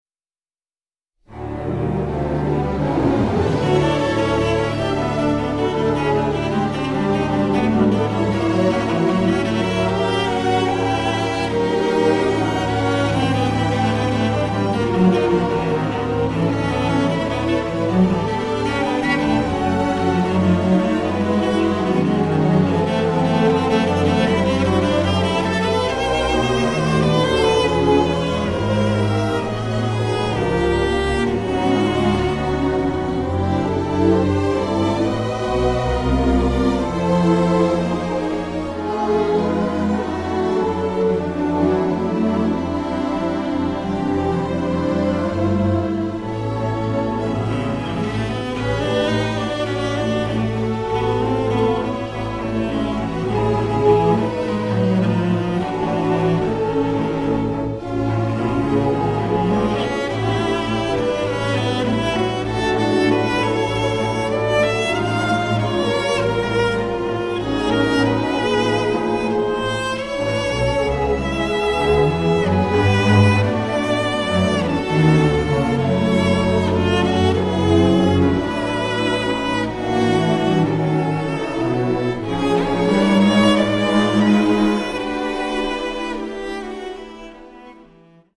Voicing: Orch w/ So